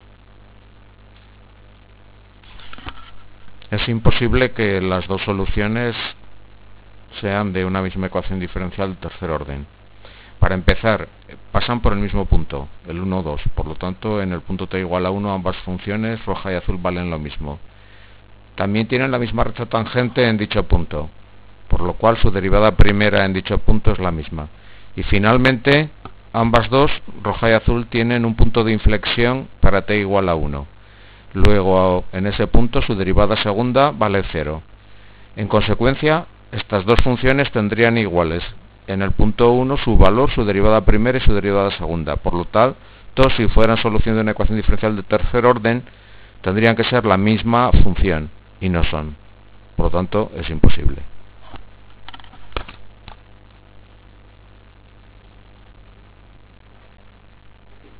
Una solución hablada